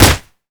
kick_heavy_impact_04.wav